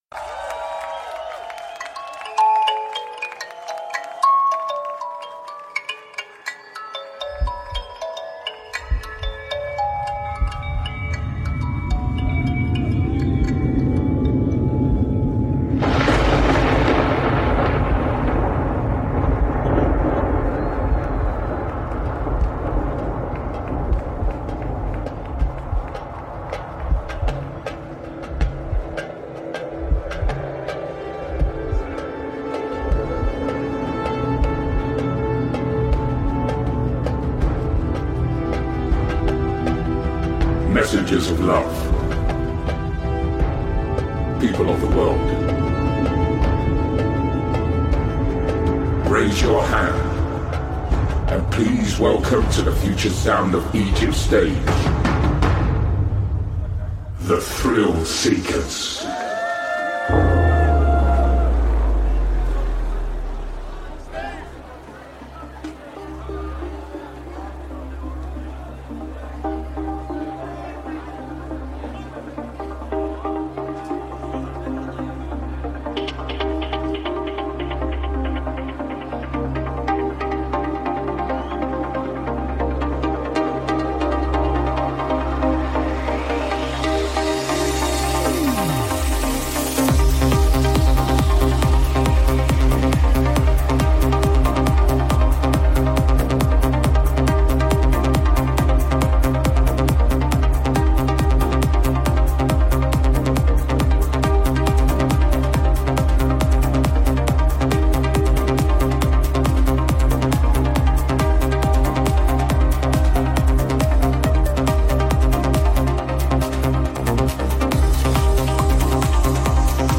Also find other EDM Livesets,
Liveset/DJ mix